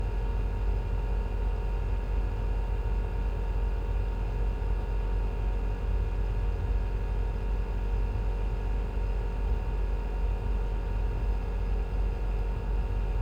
Vehicles, Electric, Car, Tesla, Model 3, Idle, Engine Compartment 03 SND66189 7.wav